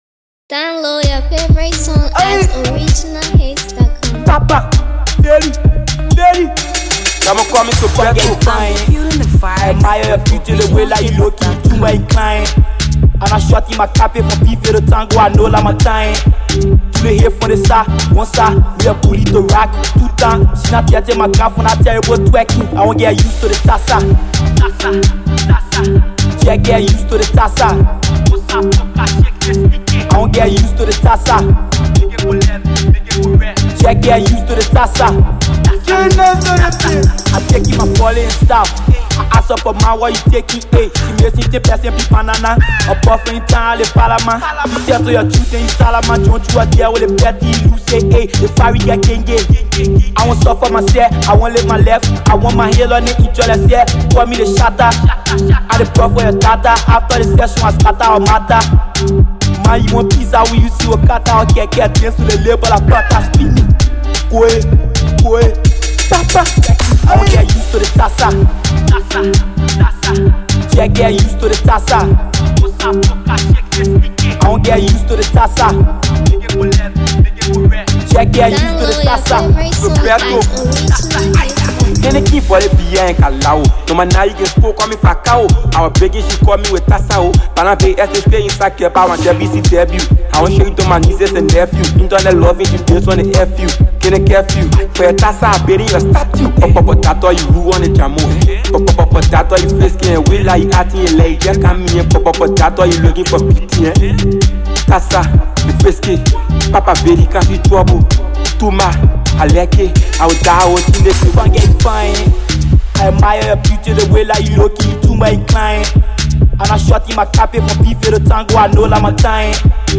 Don’t hesitate to download this amazing street jam .